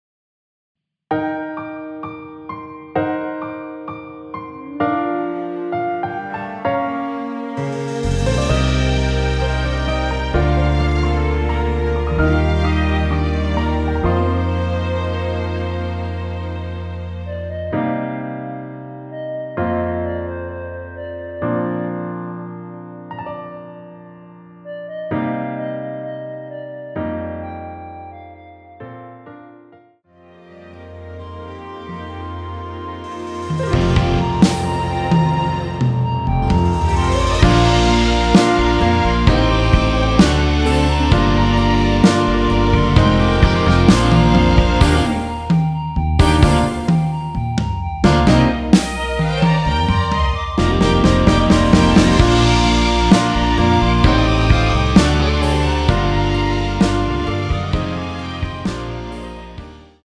원키 멜로디 포함된 MR입니다.(미리듣기 확인)
Bb
앞부분30초, 뒷부분30초씩 편집해서 올려 드리고 있습니다.